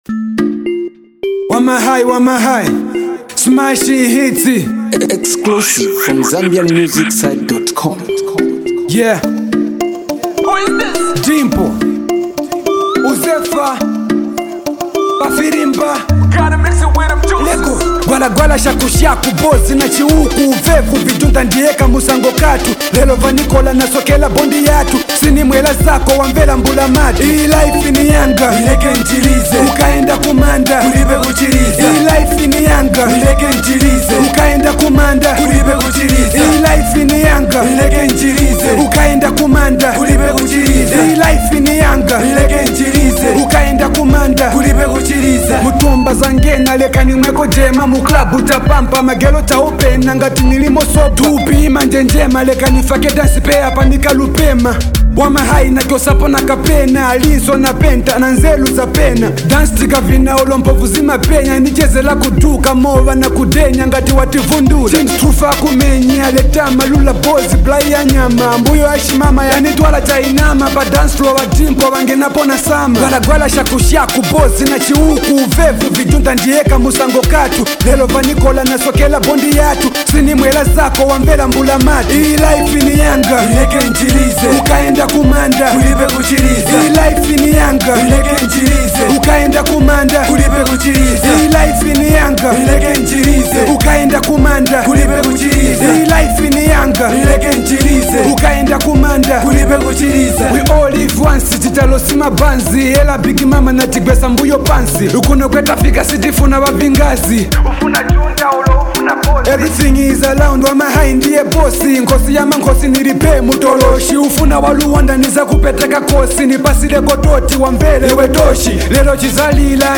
a nice danceable tune